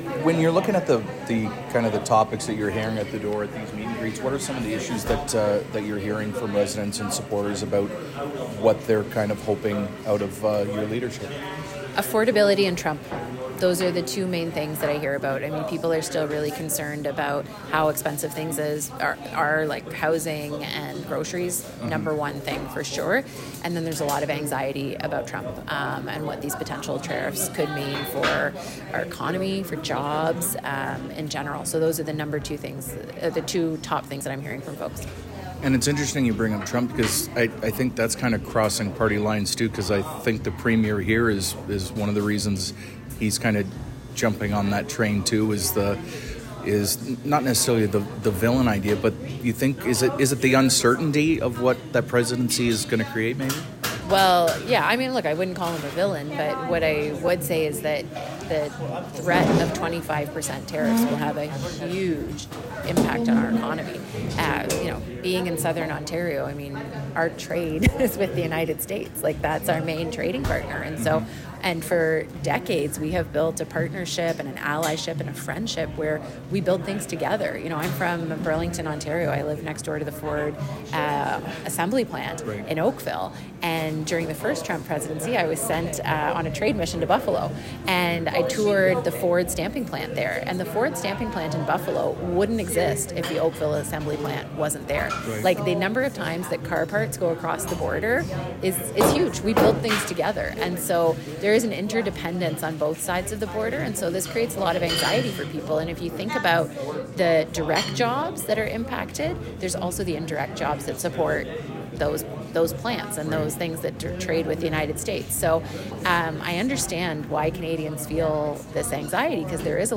After her morning interview, she went to Quinte West to meet with party members and supporters, in a private event.
karina-gould-interview.wav